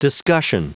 Prononciation du mot discussion en anglais (fichier audio)
Prononciation du mot : discussion